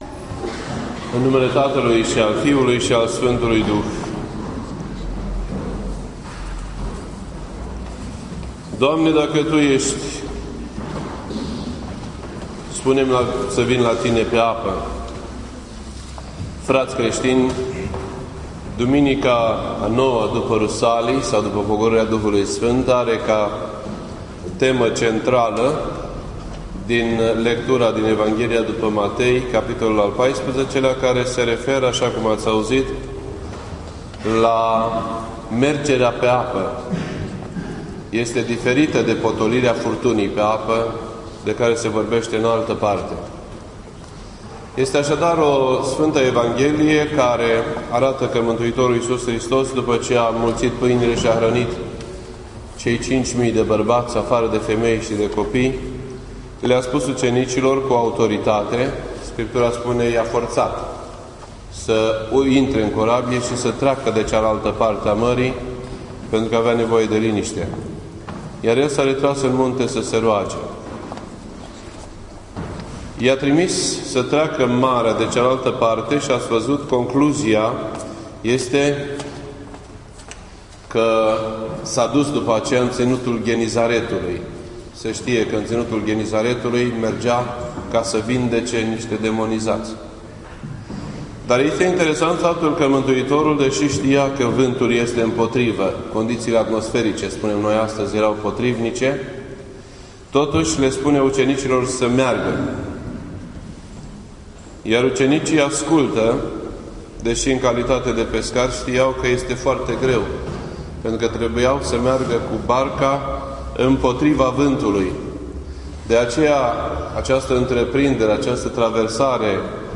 This entry was posted on Sunday, August 10th, 2014 at 12:10 PM and is filed under Predici ortodoxe in format audio.